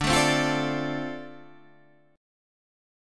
Dm7 chord